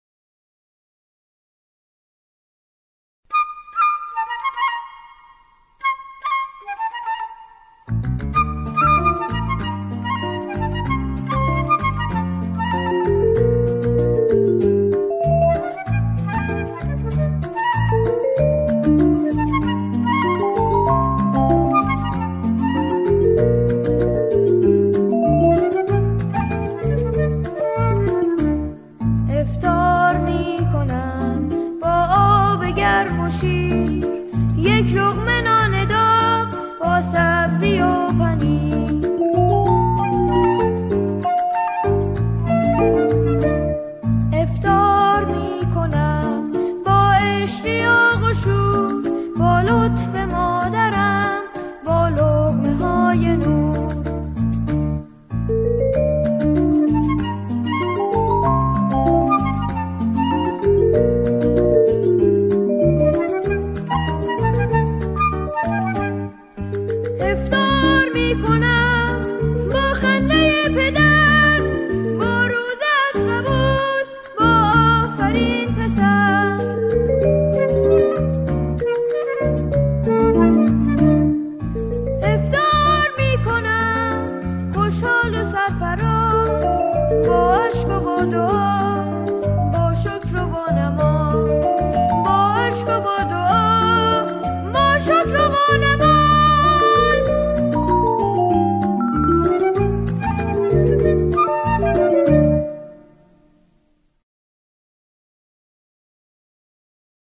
سرود های کودک